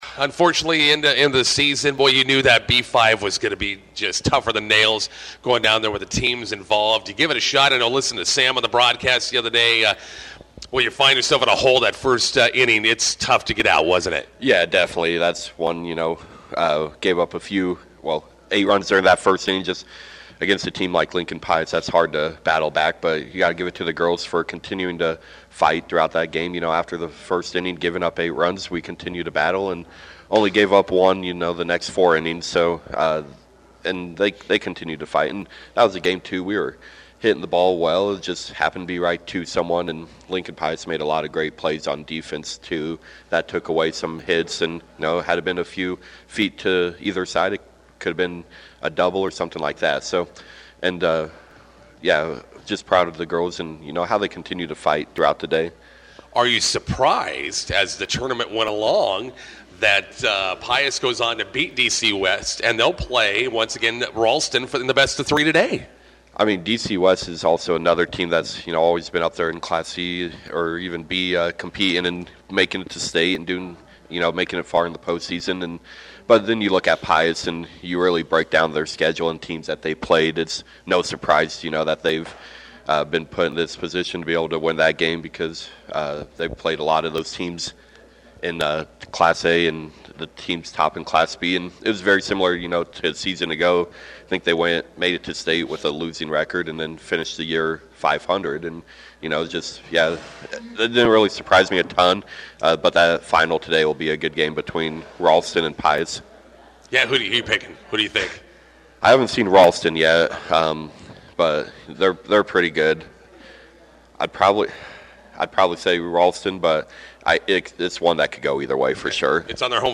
INTERVIEW: Bison softball wraps up fall season.